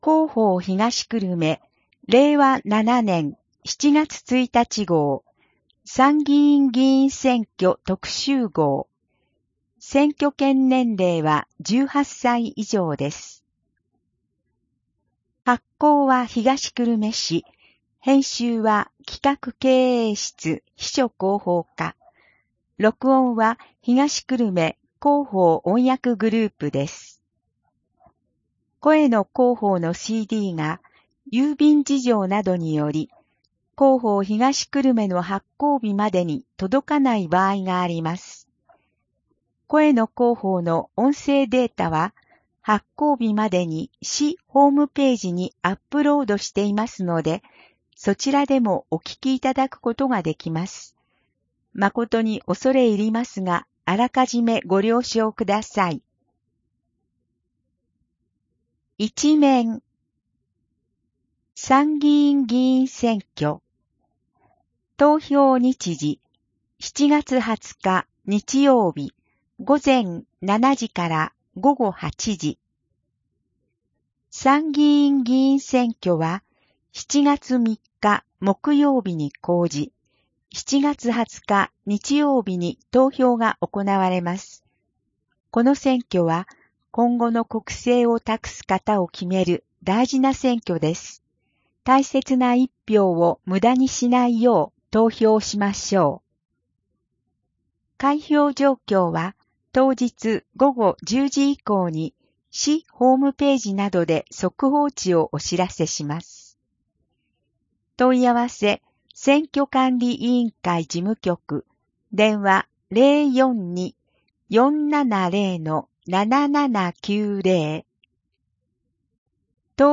声の広報（令和7年7月1日号選挙特集号）